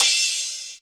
Index of /90_sSampleCDs/Best Service Dance Mega Drums/HIHAT HIP 5